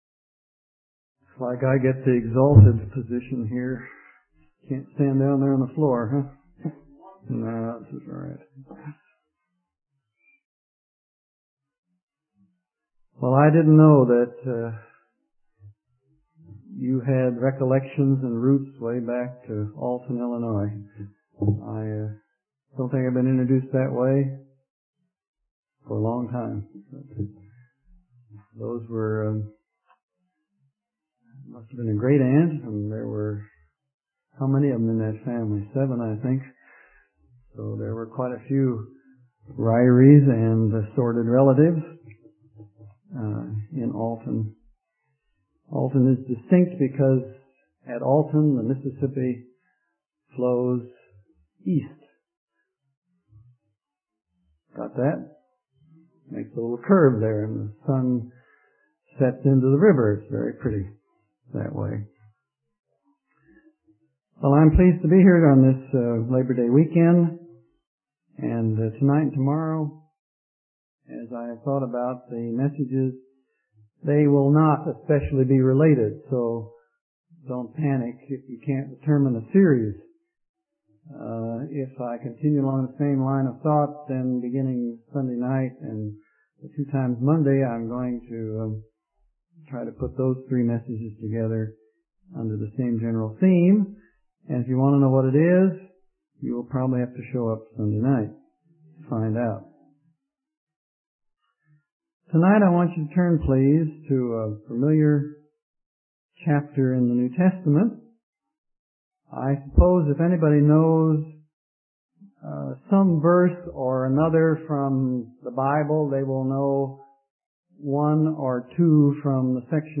In this sermon, the speaker discusses a familiar chapter in the New Testament, Matthew chapter 7.